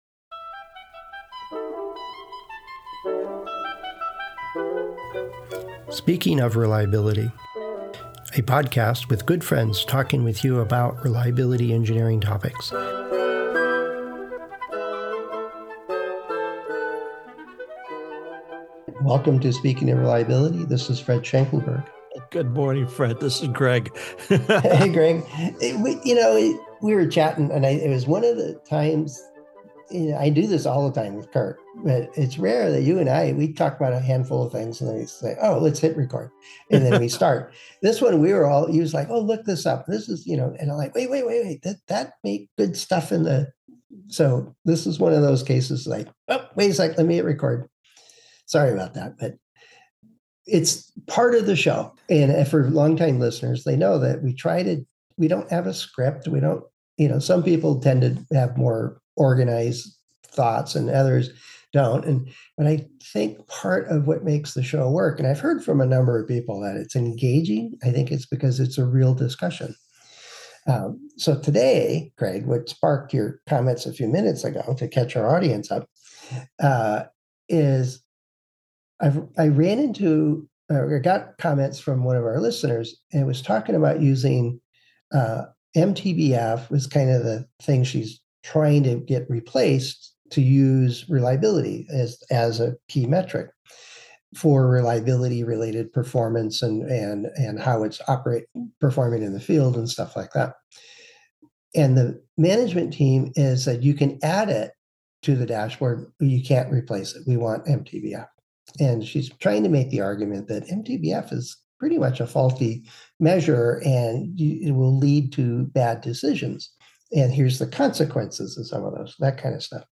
Where you can join friends as they discuss reliability topics. Join us as we discuss topics ranging from design for reliability techniques to field data analysis approaches.